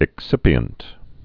(ĭk-sĭpē-ənt)